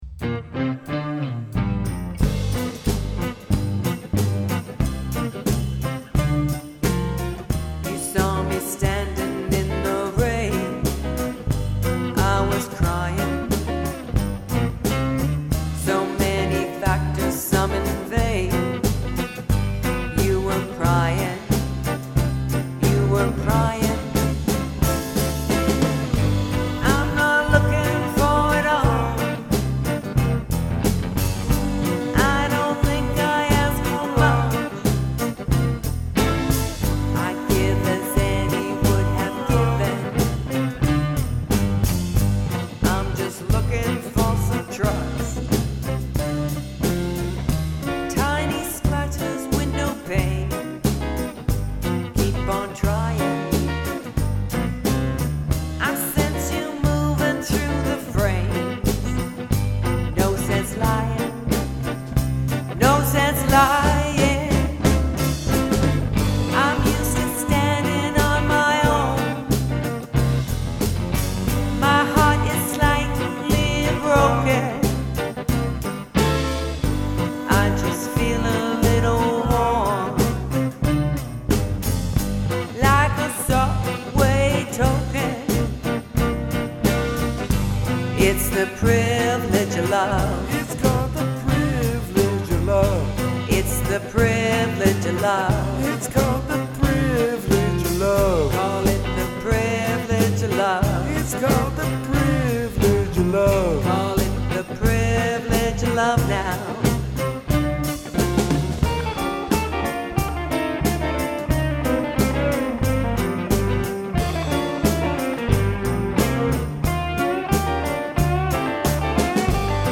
a distinct twist of R & B psychedelic rock
Vocals and Bass
Vocals and Guitar create a pleasant musical blend